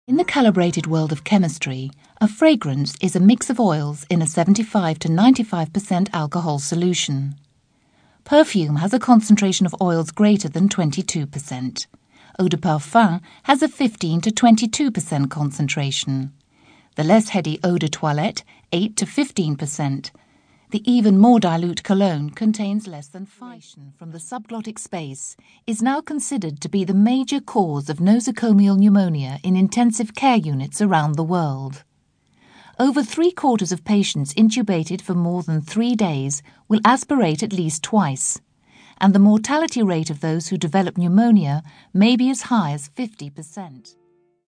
Soothing, gentle and informative.
Corporate
corporate.mp3